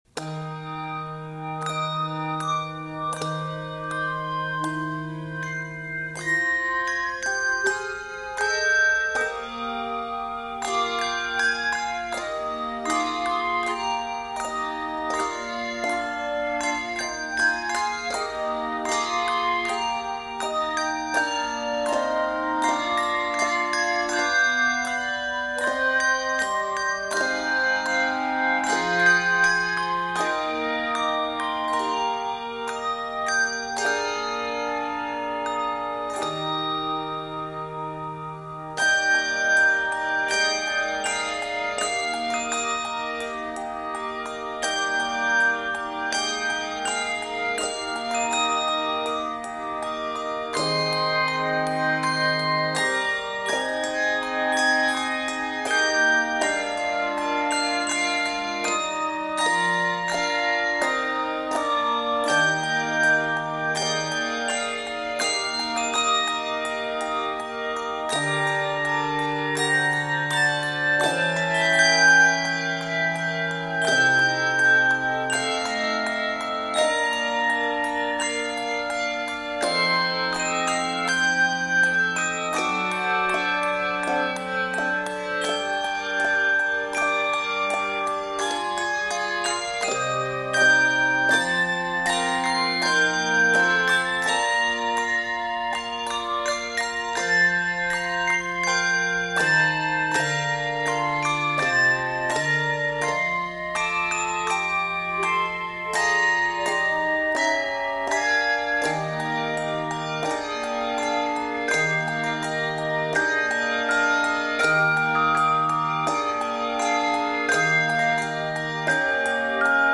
for handbells and optional handchimes